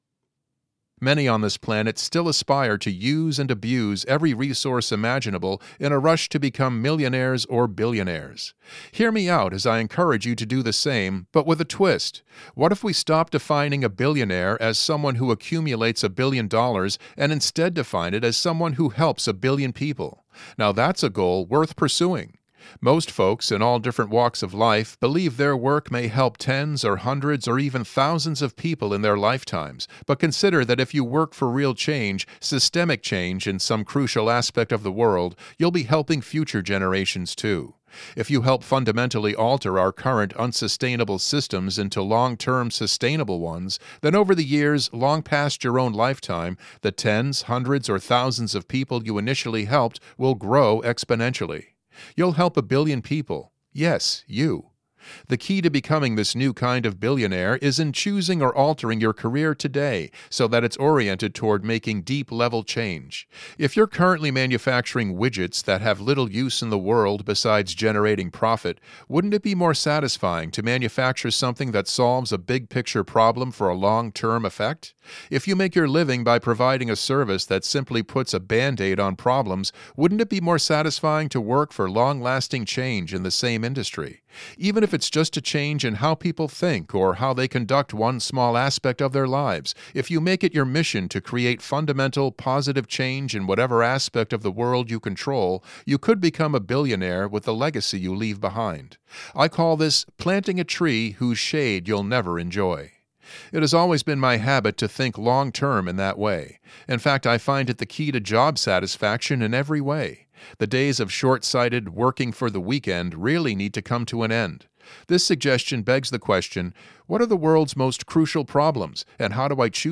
Available in Audiobook!